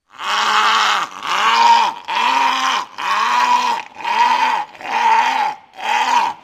Кричит